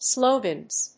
/ˈslogʌnz(米国英語), ˈsləʊgʌnz(英国英語)/